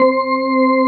20ORGN01  -R.wav